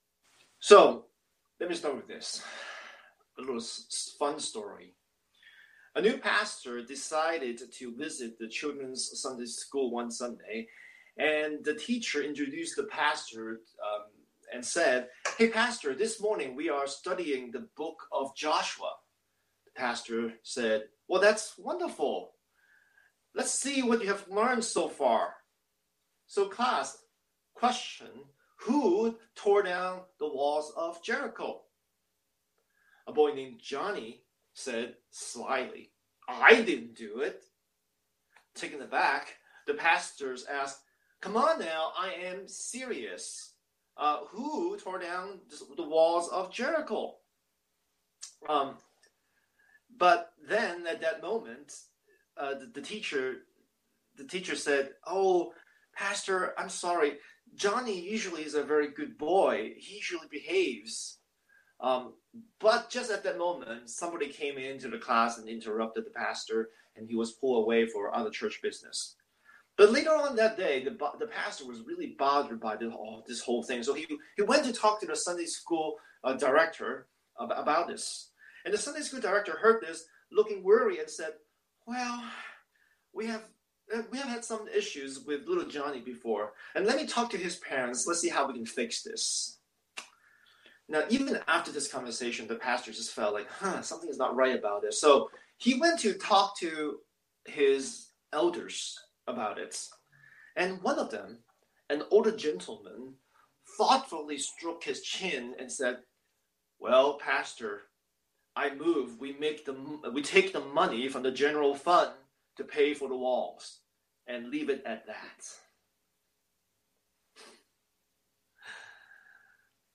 Scripture: Joshua 5:1-15 Series: Sunday Sermon